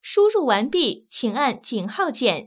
ivr-finished_pound_hash_key.wav